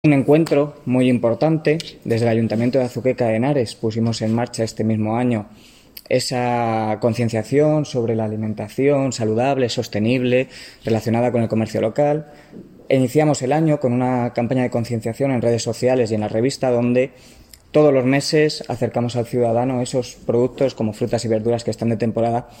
Declaraciones del concejal de Desarrollo Sostenible, Rodrigo Vasco